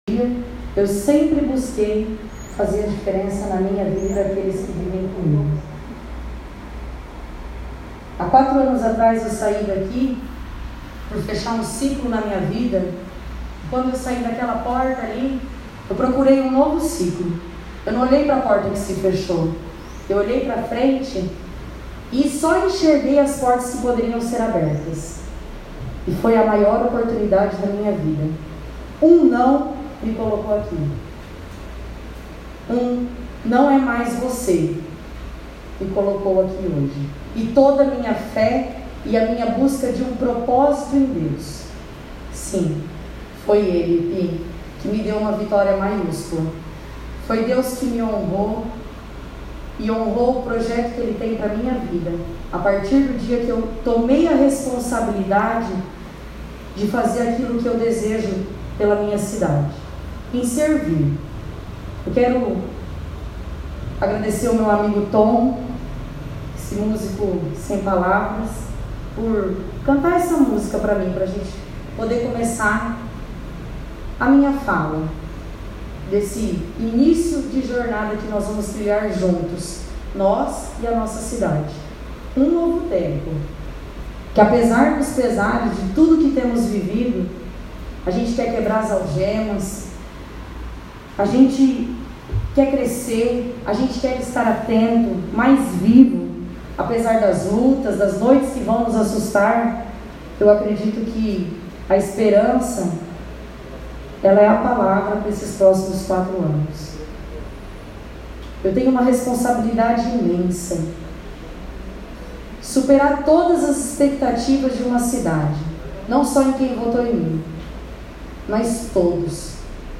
Respeitando as regras de prevenção do contágio do coronavírus, o protocolo da casa não permitiu a entrada do público e o auditório do plenário Joaquim Gorgulho esteve vazio.
Captamos o seu áudio no auditório do plenário. Ouça aqui o seu primeiro discurso como autoridade política máxima de Presidente Venceslau.